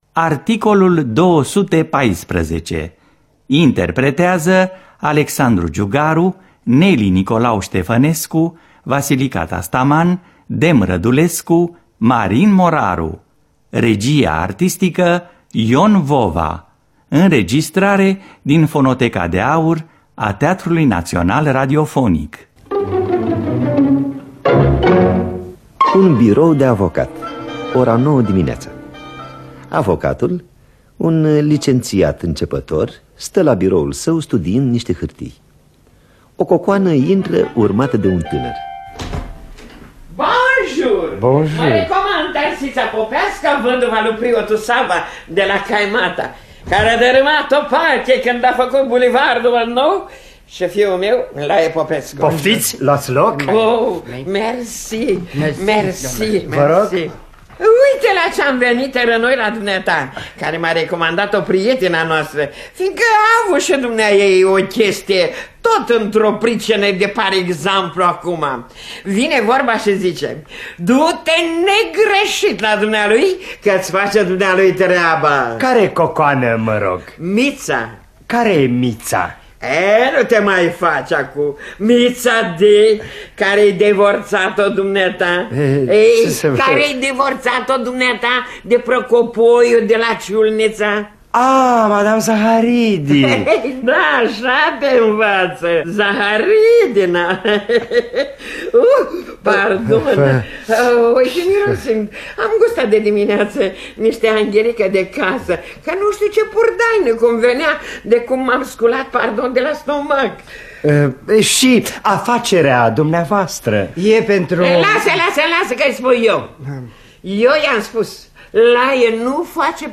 – Teatru Radiofonic Online